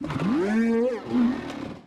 Cri de Motorizard dans Pokémon Écarlate et Violet.